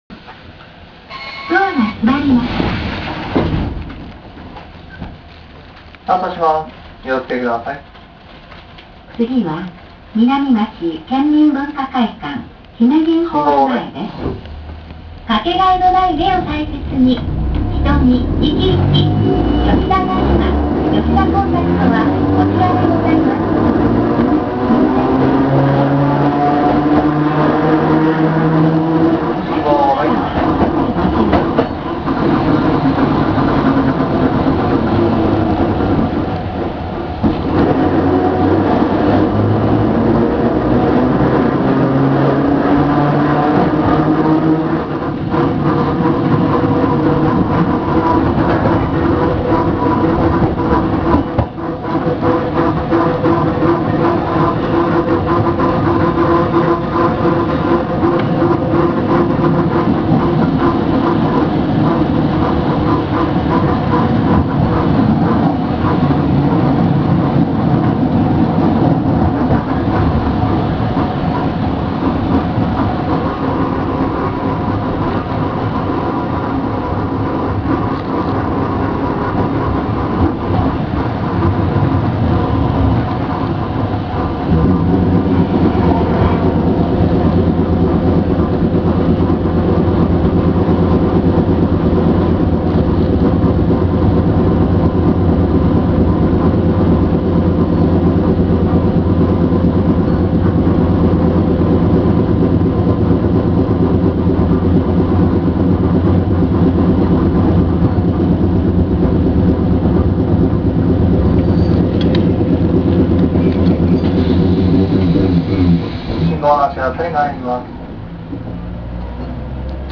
・50形走行音
【城南線】道後公園→南町@（2分1秒：661KB）…61号にて
一応前・中・後期で分けたのですが基本的に音は同じで、全て吊り掛け式。個人的には、数ある路面電車の中でもかなり派手な音を出す部類に感じました。こんな古めかしい音でありながら、警笛は近年の路面電車で増えているミュージックホーンに変更されています。